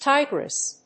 音節ti・gress 発音記号・読み方
/tάɪgrɪs(米国英語)/